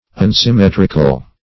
Unsymmetrical \Un`sym*met"ric*al\, a.